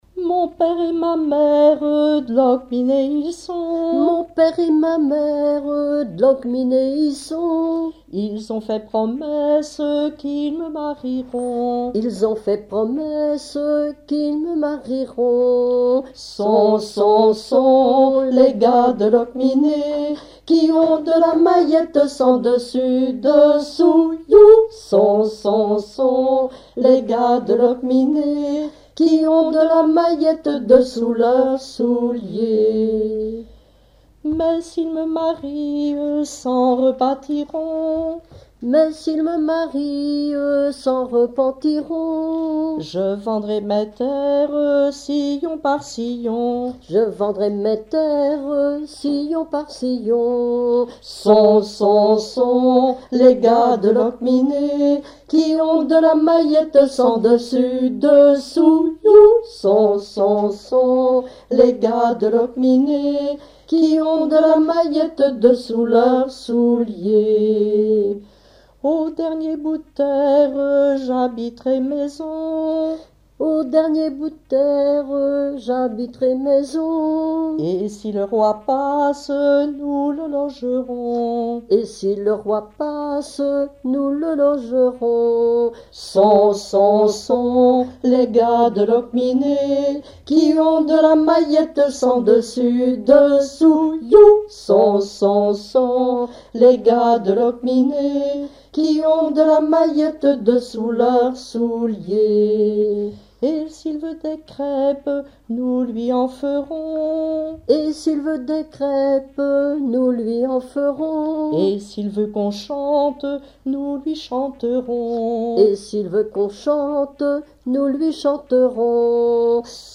ronde d'école
rondes enfantines
Témoignages et chansons
Pièce musicale inédite